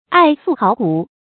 愛素好古 注音： ㄞˋ ㄙㄨˋ ㄏㄠˋ ㄍㄨˇ 讀音讀法： 意思解釋： 指愛好樸質，不趨時尚。